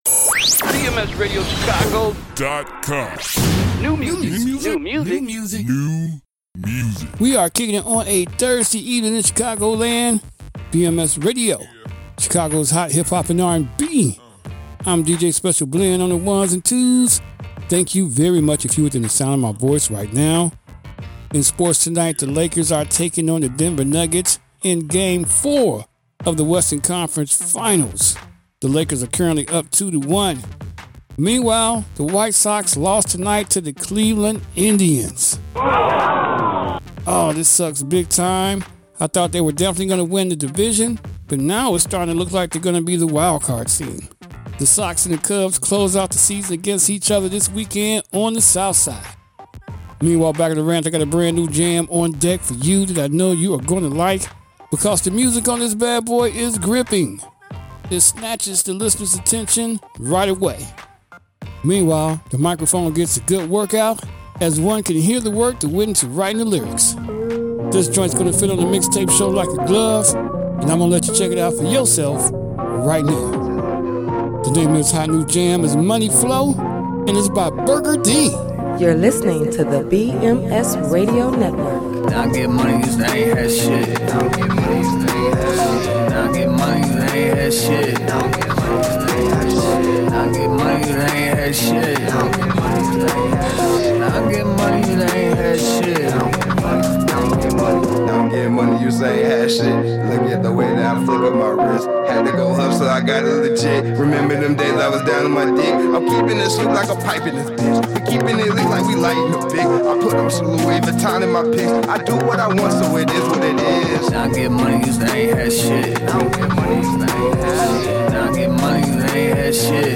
Plus, this jam packs a super catchy hook.
Chicago’s Hot Hip-Hop and R&B.